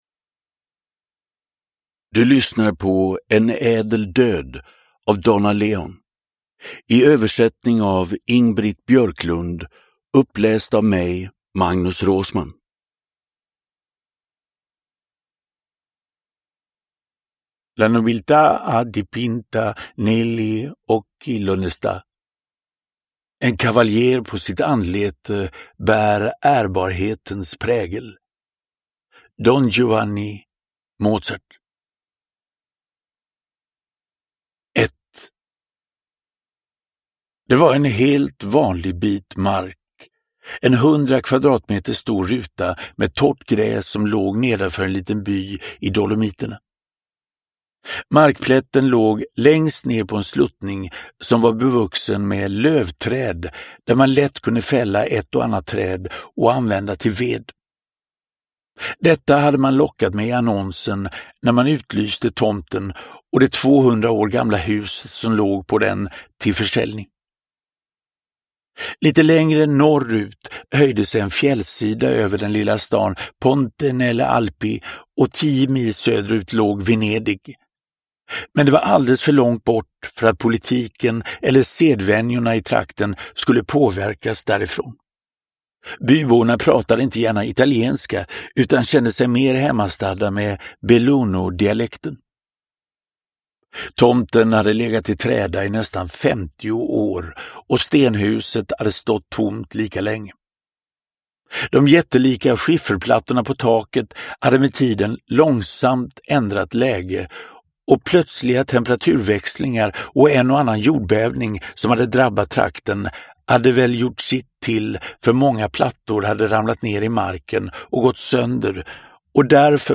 En ädel död – Ljudbok – Laddas ner
Uppläsare: Magnus Roosmann